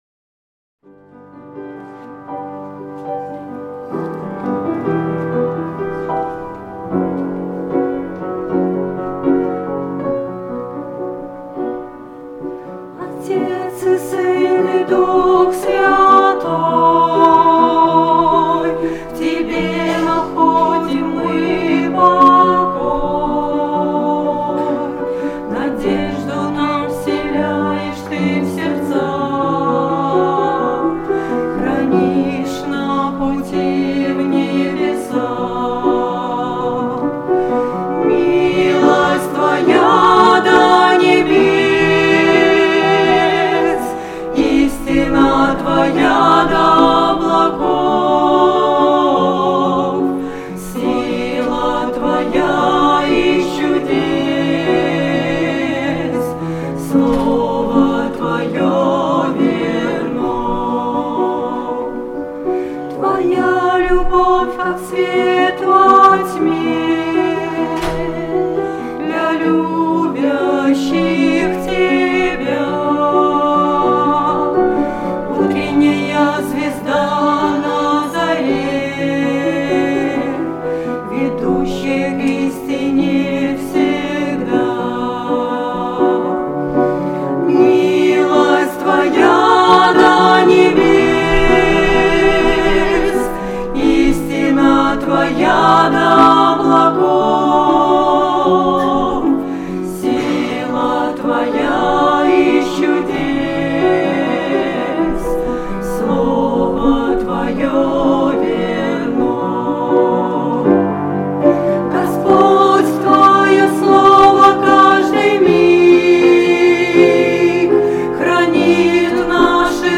19-06-16 / Отец, и Сын, и Дух Святой (трио)